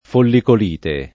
follicolite [ follikol & te ]